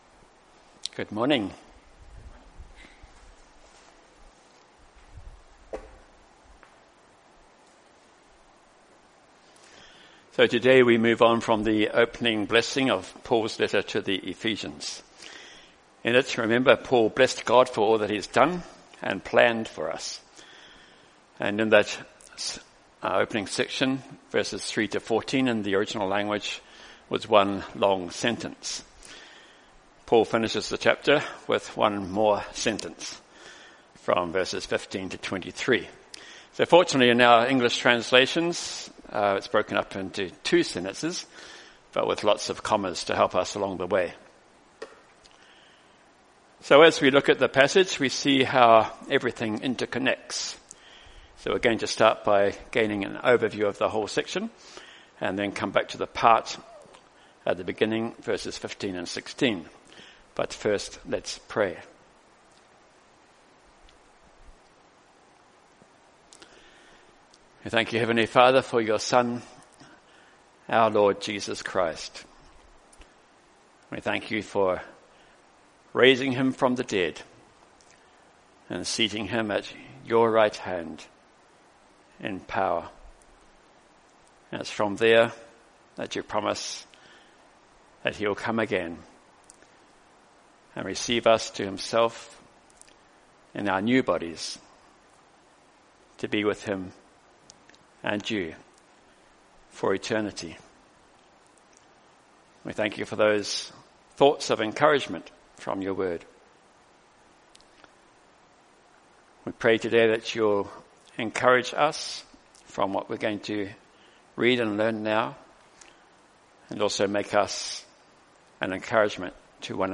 Bible Text: Ephesians 1:15-23 | Preacher